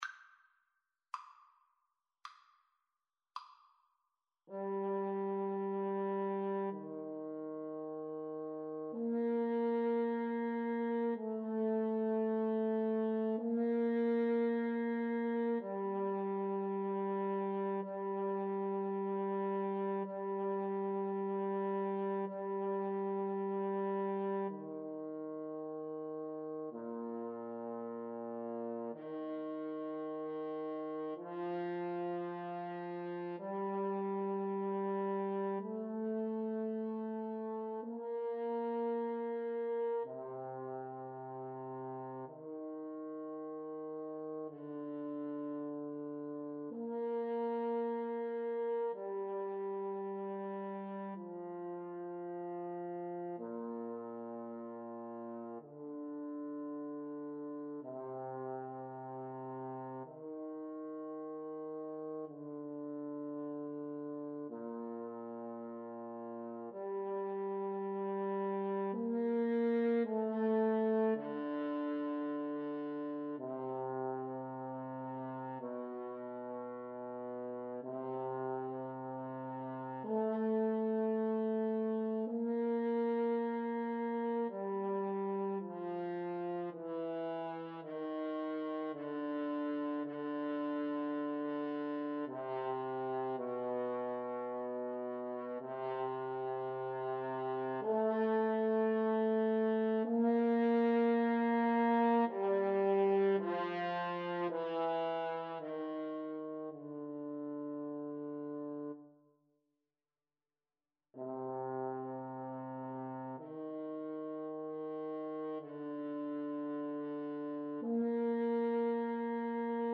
12/8 (View more 12/8 Music)
Andante cantabile, con alcuna licenza (. = 54)
Classical (View more Classical French Horn Duet Music)